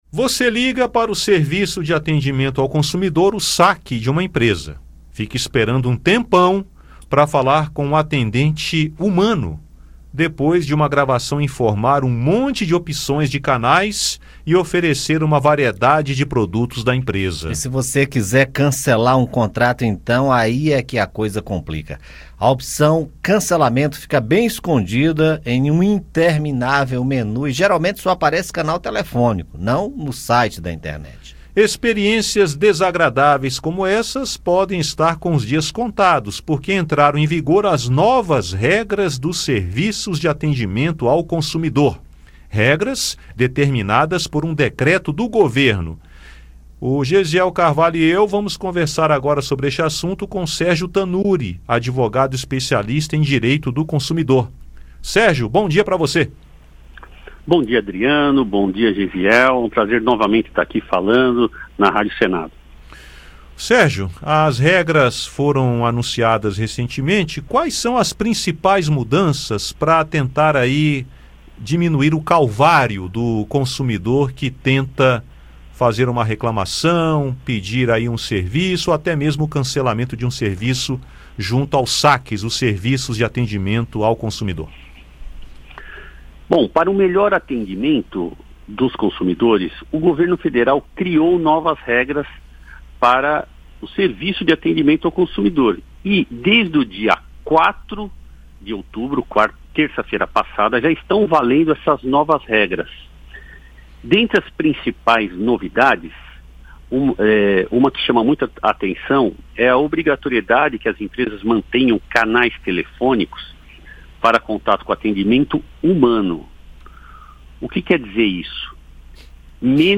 Rádio Senado Entrevista
Aqui você escuta as entrevistas feitas pela equipe da Rádio Senado sobre os assuntos que mobilizam o país e sobre as propostas que estão sendo discutidas no Parlamento.
Advogado explica novas regras para os Serviços de Atendimentos ao Consumidor